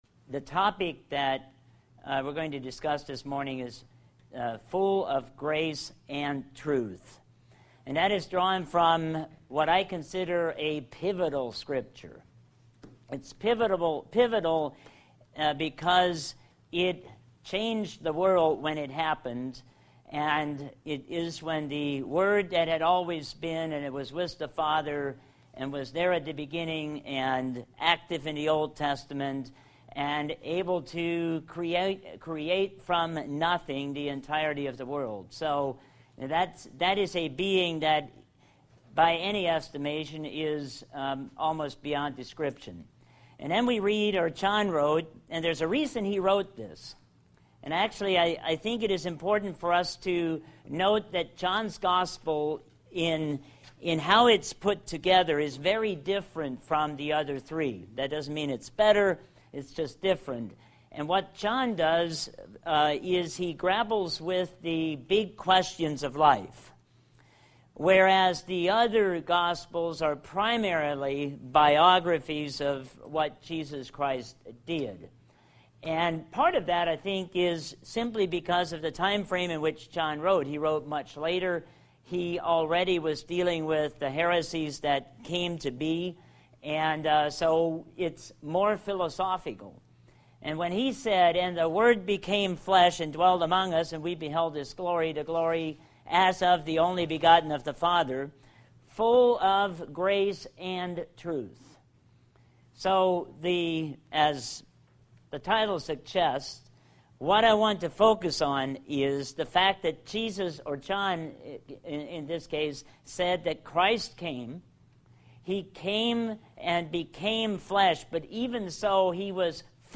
Bible Study: Full of Grace and Truth